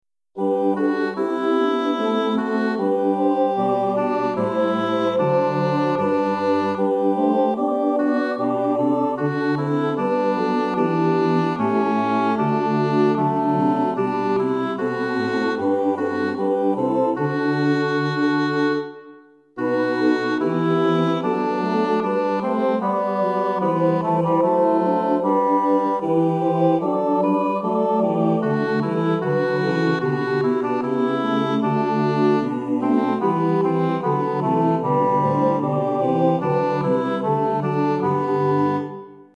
Nu vilar folk och länder alt
nu vilar folk_alt.mp3